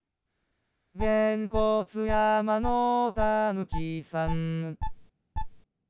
Singing Voice Synthesis
Below are some sample wav files of singing voice synthesized WITH and WITHOUT time-lag models:
WITHOUT "Genkotsu Yama No Tanuki San" (Japanese Children's Song)